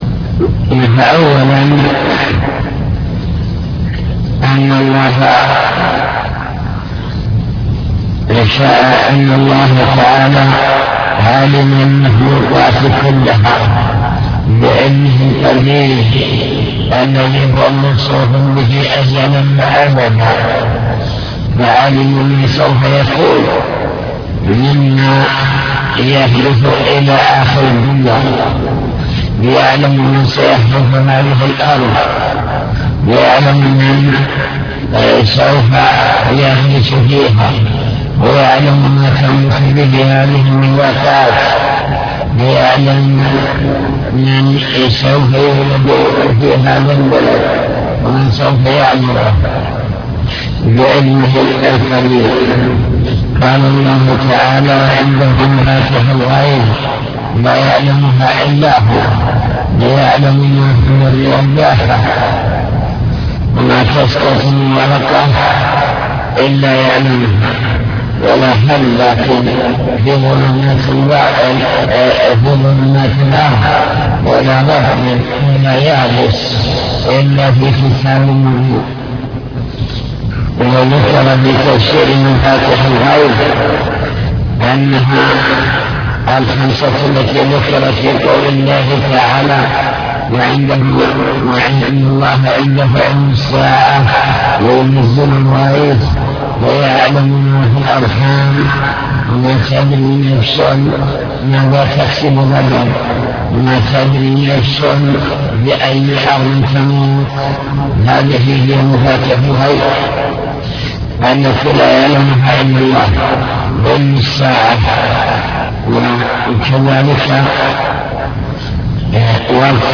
المكتبة الصوتية  تسجيلات - كتب  شرح كتاب بهجة قلوب الأبرار لابن السعدي شرح حديث كل شيء بقدر حتى العجز والكيس